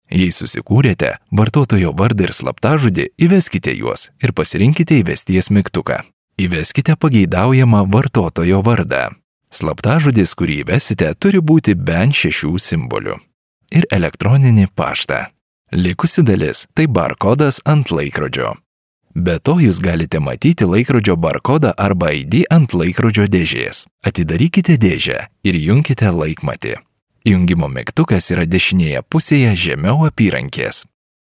Lithuanian voice over artist native
Sprechprobe: eLearning (Muttersprache):